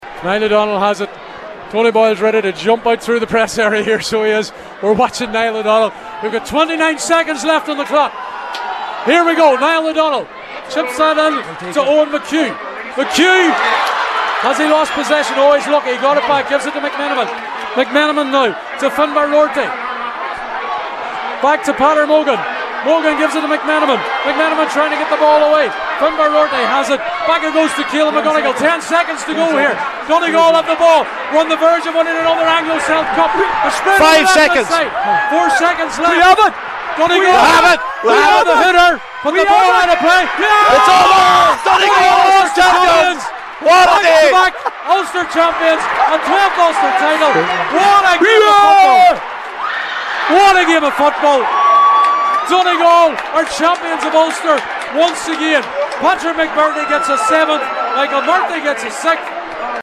And this is what it sounded like as the Highland commentary team